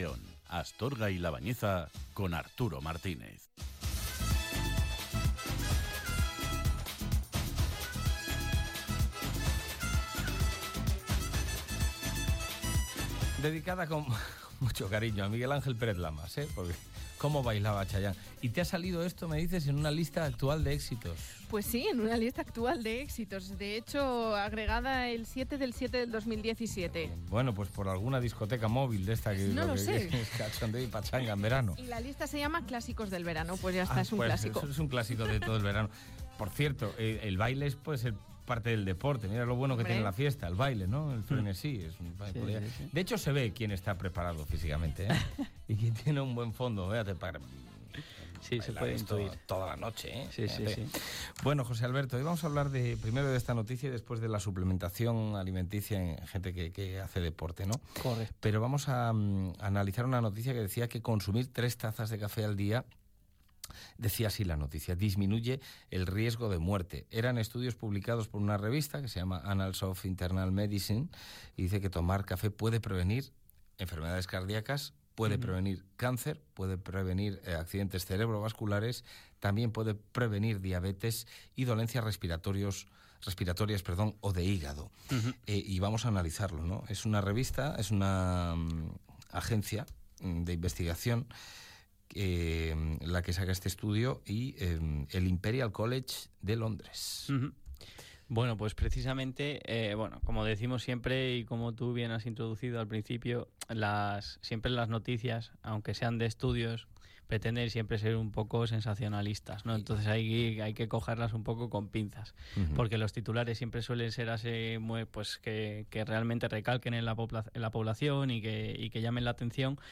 Hoy os traigo el centésimo décimo octavo programa de la sección que comenzamos en la radio local hace un tiempo y que hemos denominado Es Saludable.